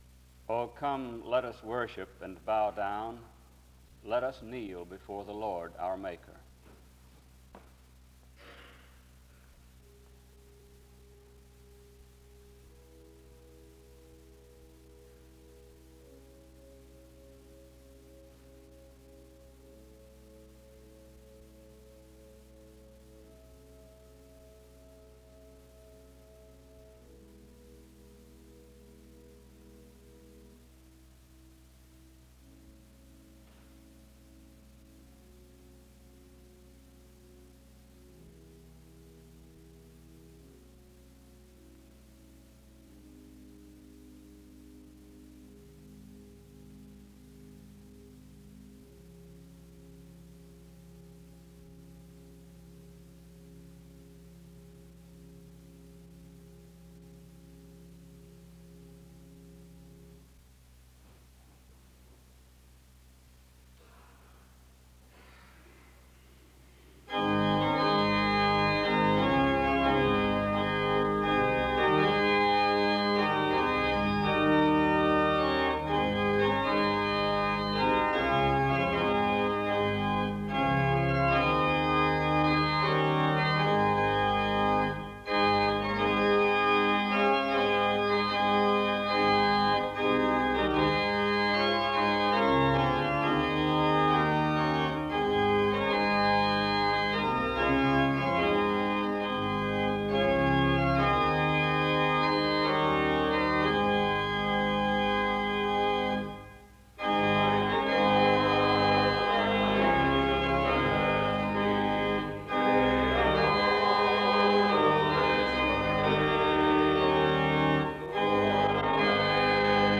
The service opens with music from 0:15-5:09. A prayer is offered from 5:17-7:11.